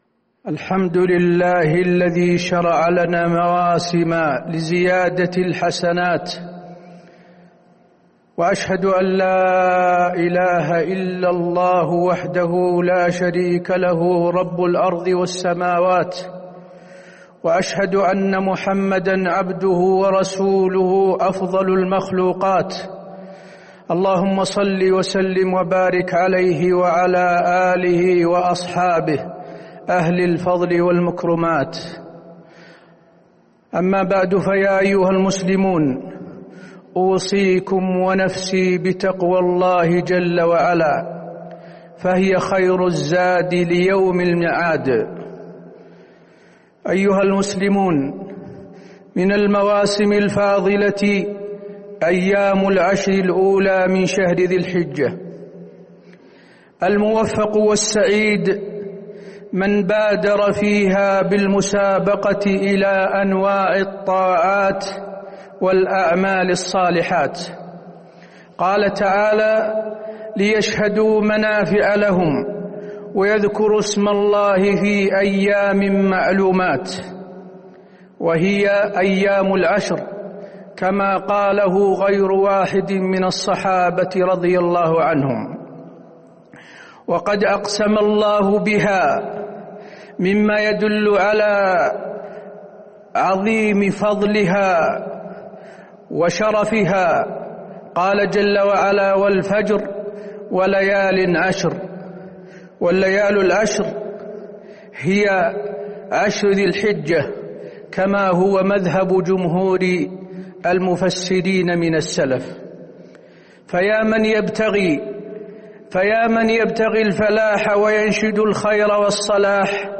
تاريخ النشر ٢٦ ذو القعدة ١٤٤١ هـ المكان: المسجد النبوي الشيخ: فضيلة الشيخ د. حسين بن عبدالعزيز آل الشيخ فضيلة الشيخ د. حسين بن عبدالعزيز آل الشيخ فضل عشر ذي الحجة